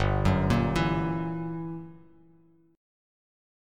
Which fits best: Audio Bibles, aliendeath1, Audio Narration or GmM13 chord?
GmM13 chord